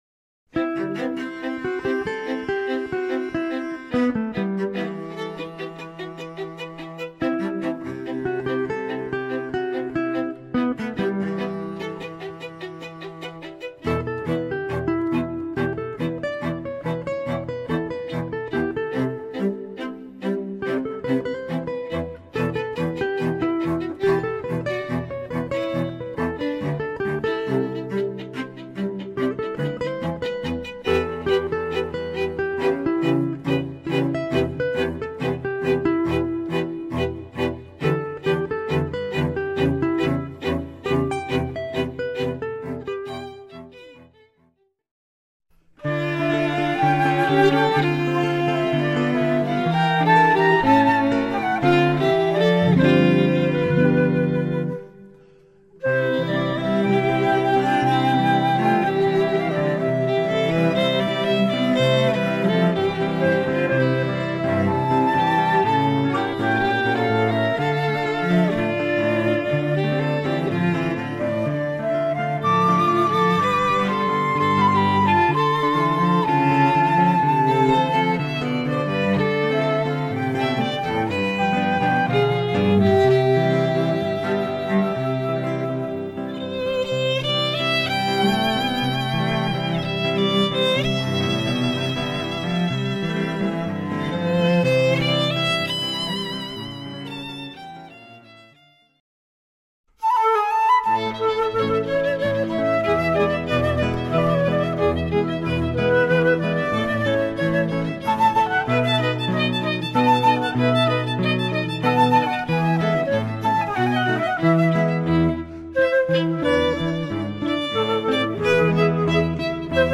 String & Flute Quartet